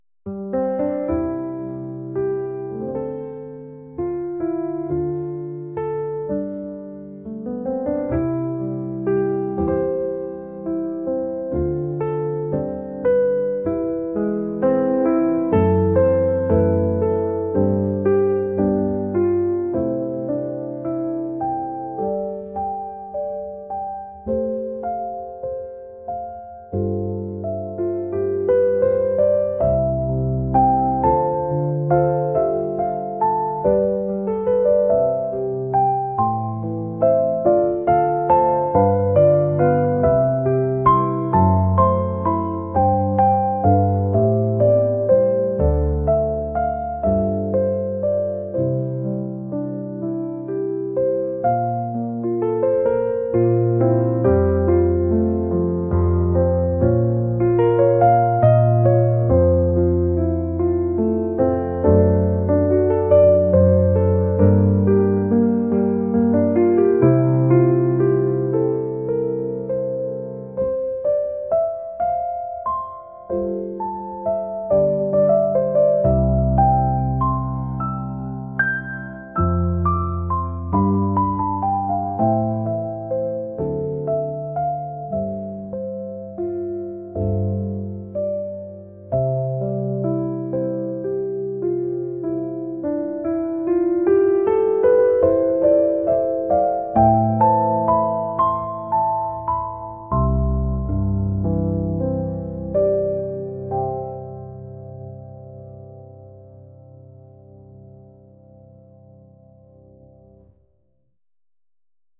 jazz | lounge | lofi & chill beats